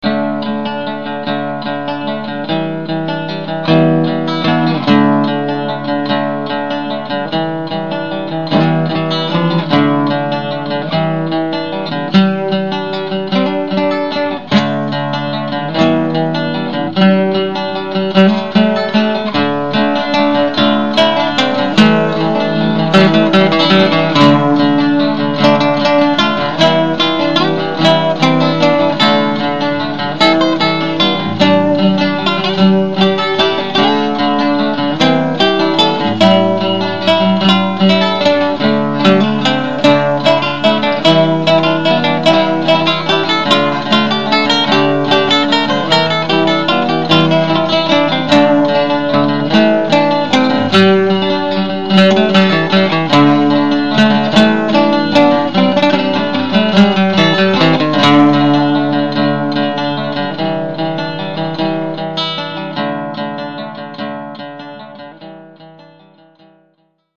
Recorded on Christmas, I did this w/ my nylon string acoustic and my cheap-ass Logitech webcam! There are 3 layers here, and it doesn't sound great, but I like the style. I was moving from New York to Maine and so this song represented the stepping stones as you move through life.
I didn't have my regular setup, so this was the first time I used the webcam to record.